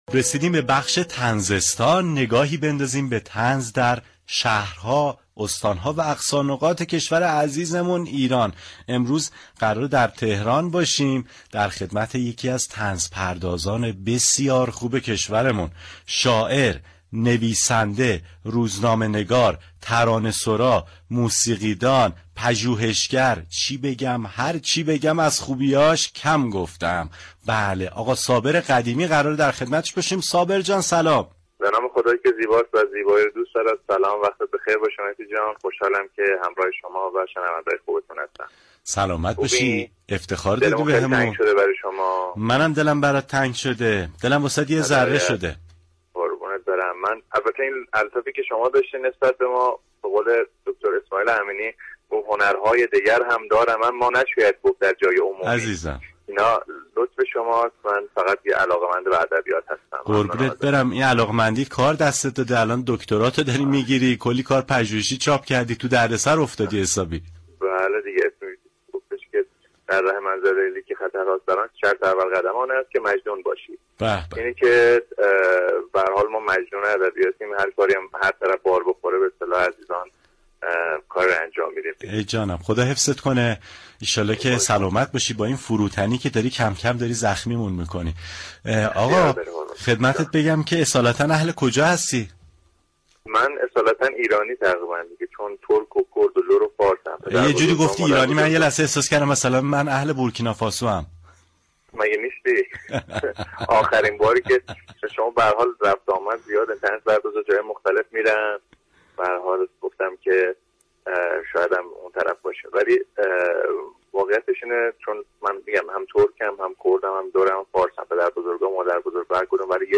گفتگوی تلفنی